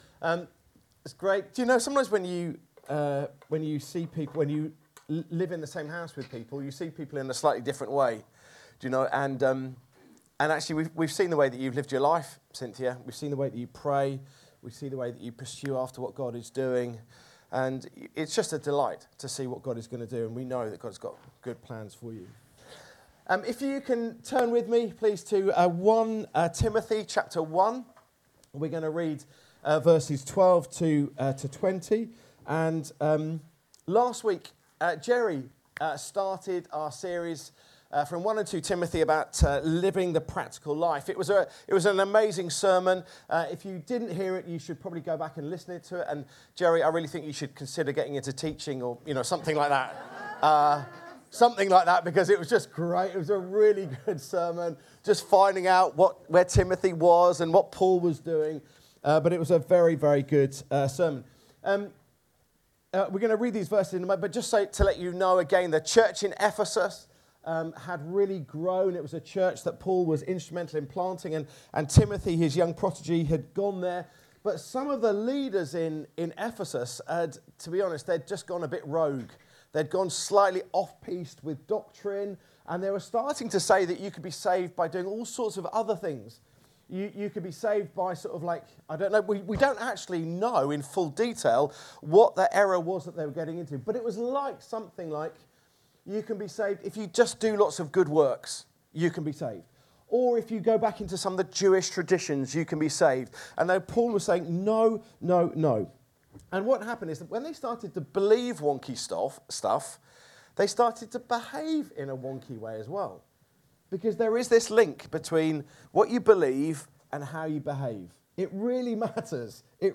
Download God’s grace to Paul | Sermons at Trinity Church